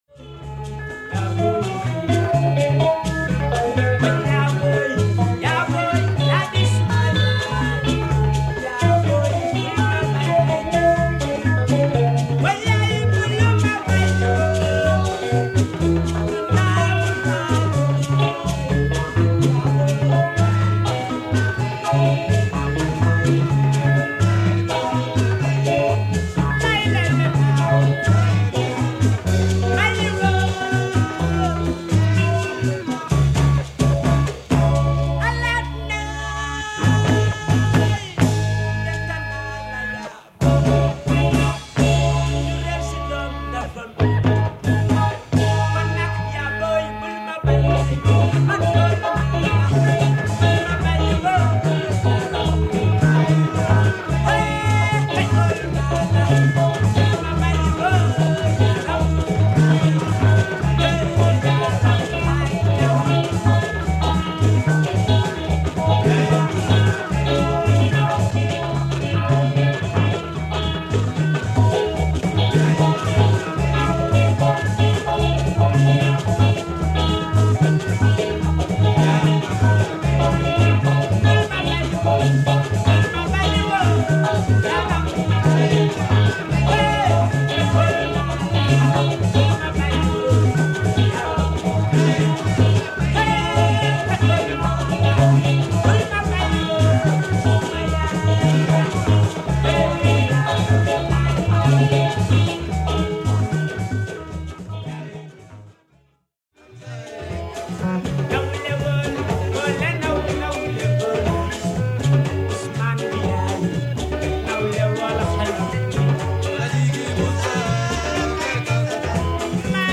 西アフリカ的な暖かさとリズム/グルーヴの雑食性、そしてアナログなサイケデリック感が心地よく散りばめられた魅惑の音楽。